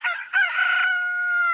Click Me for a FUNNY Noise!
Rooster.ra